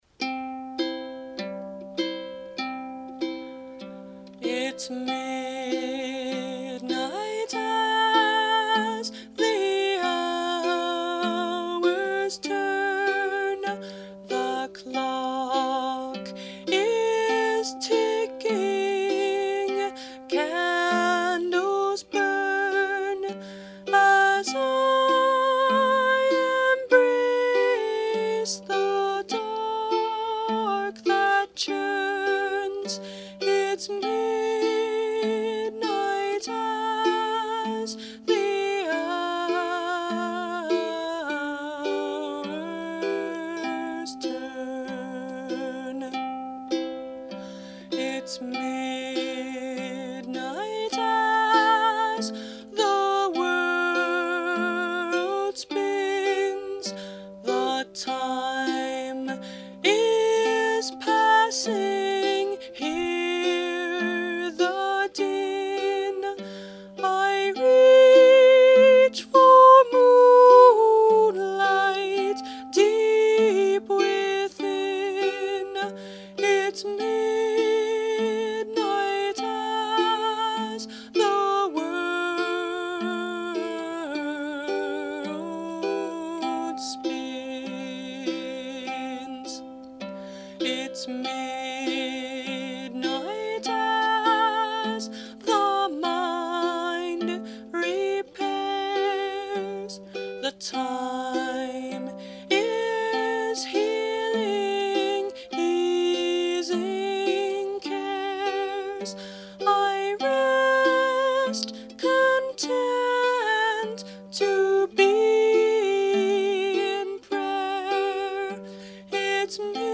Written as a Nocturn, or night song, it’s natural this would be set to music.